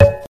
Bongo 1.wav